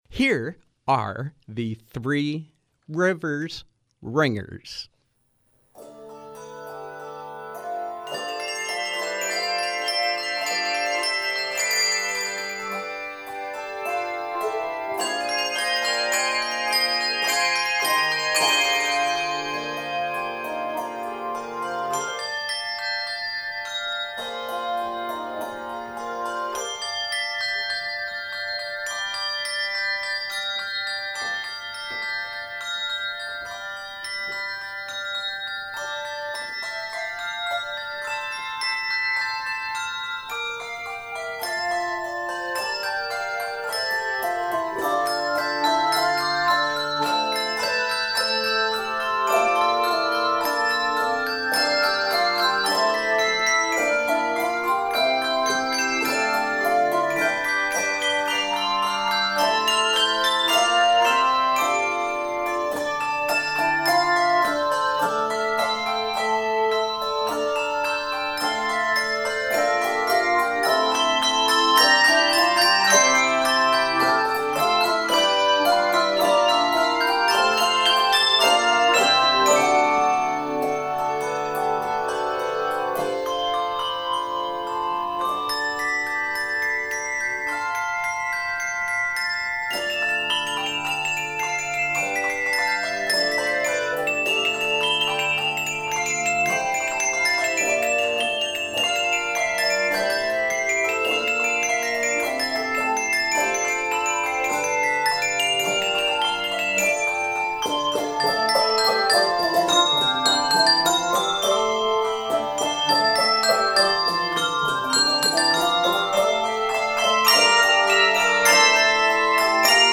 Live Music: Three Rivers Ringers
From 12/09/2017: A holiday performance with Three Rivers Ringers bell ensemble.